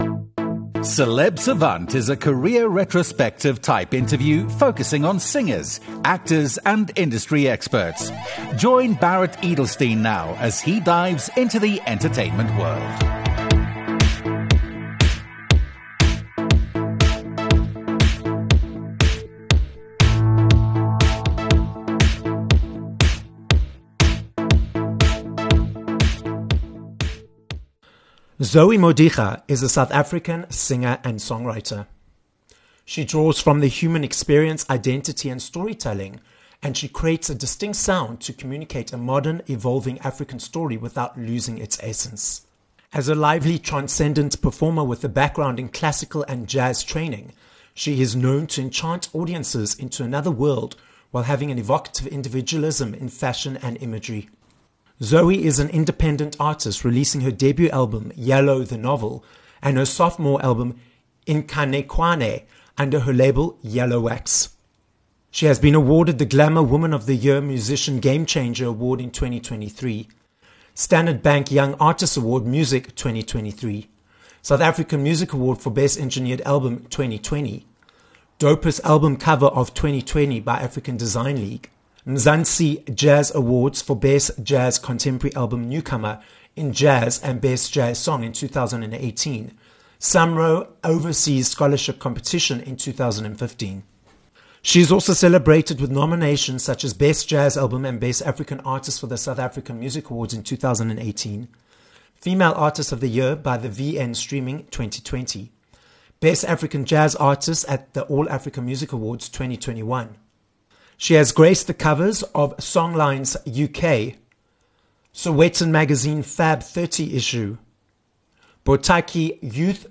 Interview
This episode of Celeb Savant was recorded live in studio at Solid Gold Podcasts, Randburg, Johannesburg, South Africa.